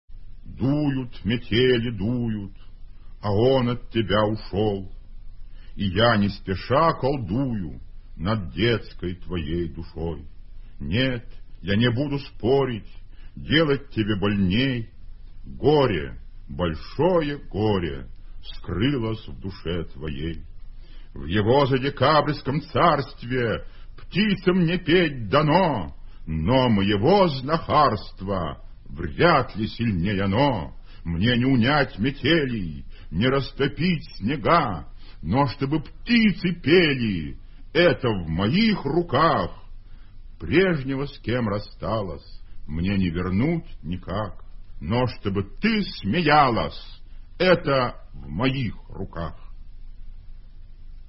1. «Владимир Солоухин – Дуют метели, дуют… (читает автор)» /
vladimir-solouhin-duyut-meteli-duyut-chitaet-avtor